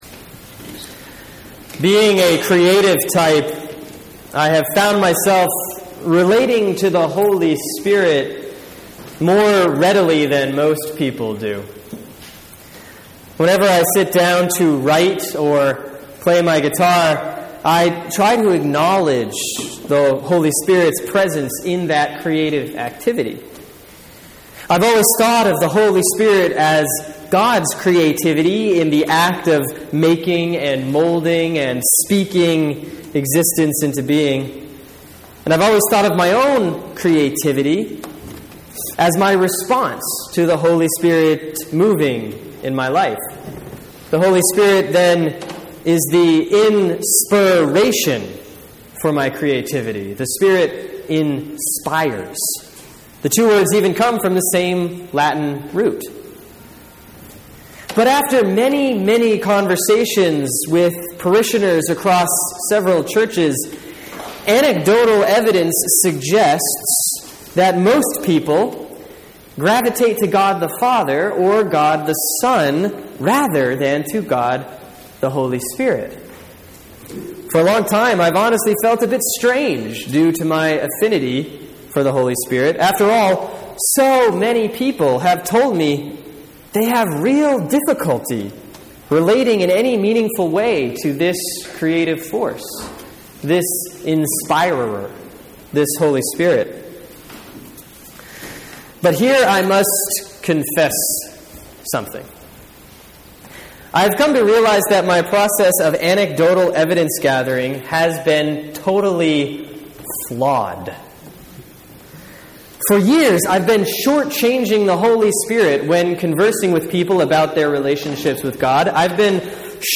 Sermon for Sunday, June 8, 2014 || Pentecost, Year A || Acts 2:1-21